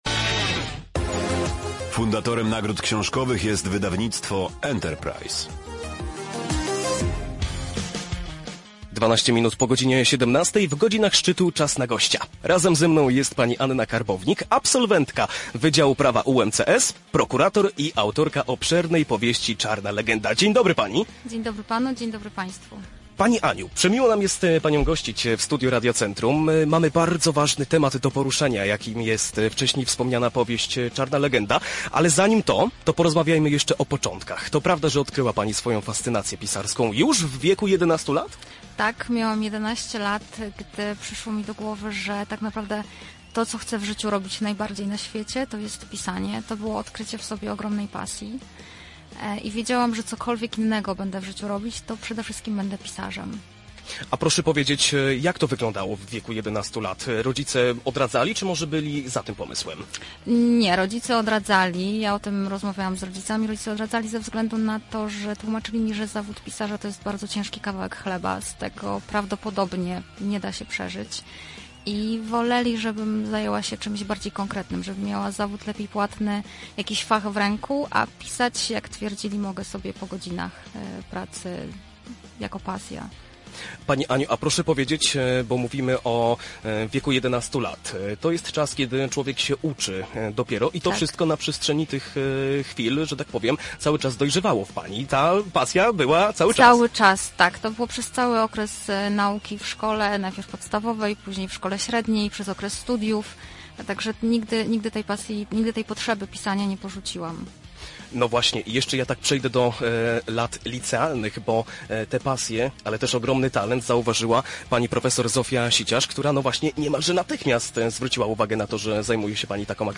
plik-wywiad.mp3